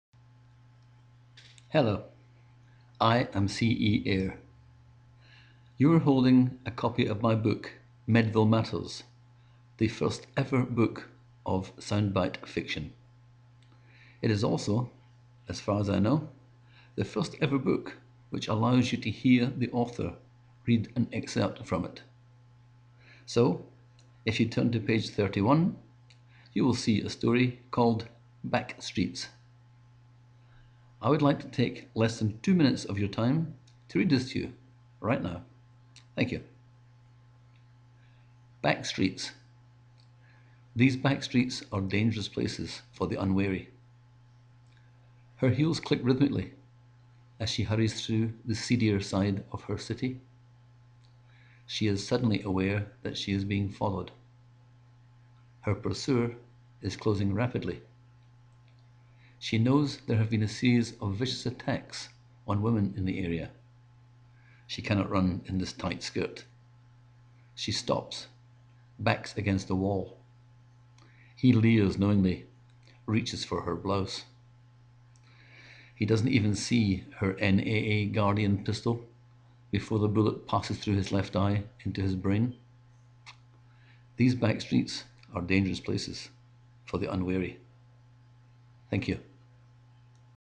Great voice; literally.
And I loved to hear you read.